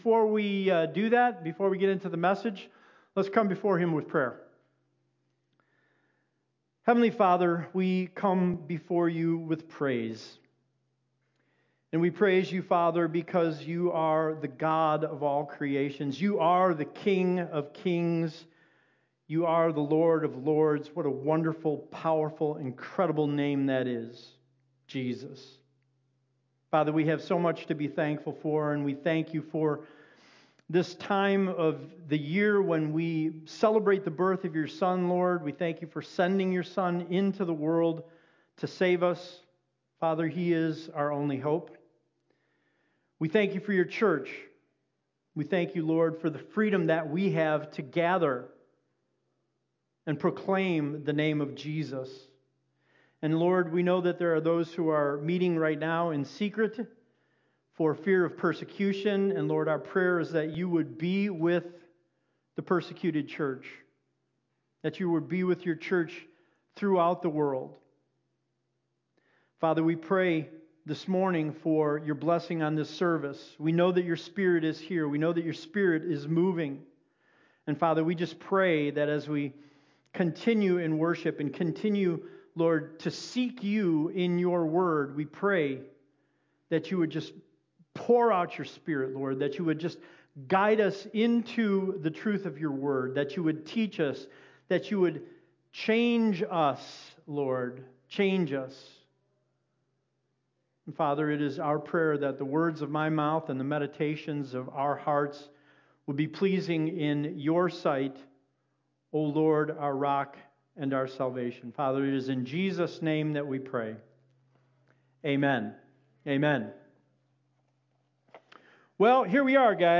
Hope Community Church of Lowell's Sunday sermon audio's online for your convience.